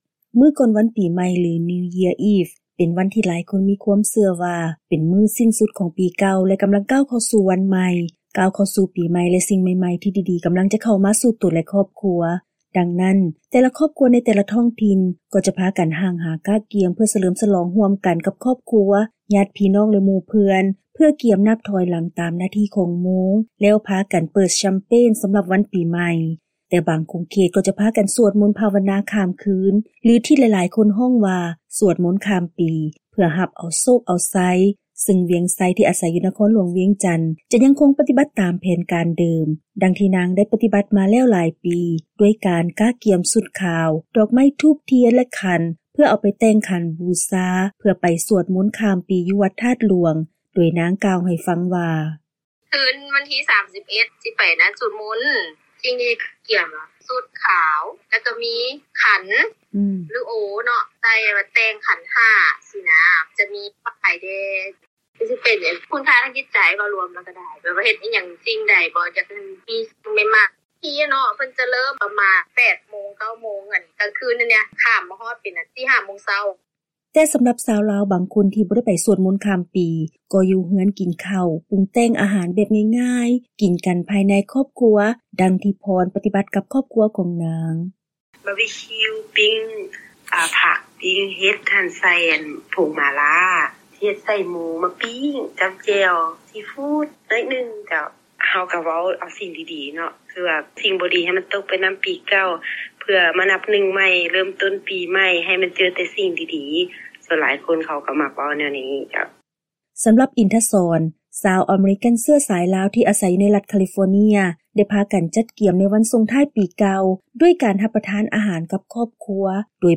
ຟັງລາຍງານກ່ຽວກັບ ການສະເຫຼີ້ມສະຫຼອງມື້ກ່ອນວັນປີໃໝ່ສາກົນ ຂອງຊາວລາວ ຢູ່ບາງຂົງເຂດຂອງໂລກ